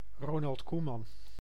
Ronald Koeman[3] (Dutch pronunciation: [ˈroːnɑlt ˈkumɑn]